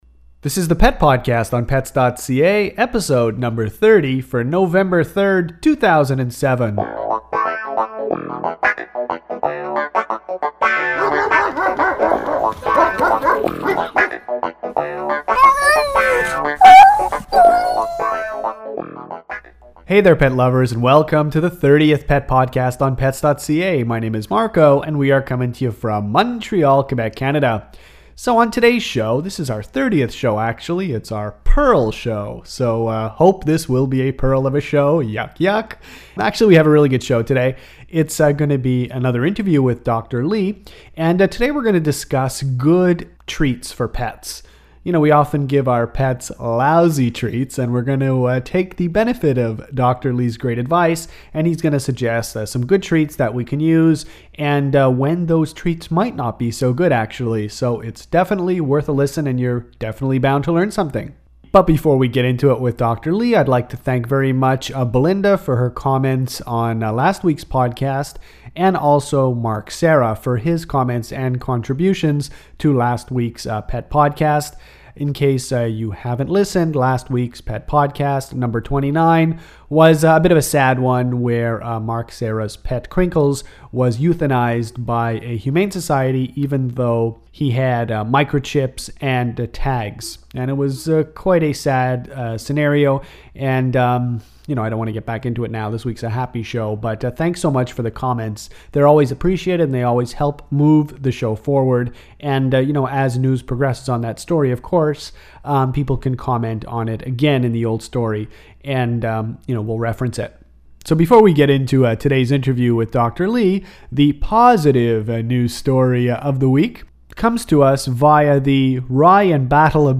In this interview we discuss giving dogs and cat treats. We talk about the ratio of treats to a healthy dog or cat food and we talk about some great treats in general. We also touch on the issue of food allergies and diseases where certain treats that are normally good, become terrible choices.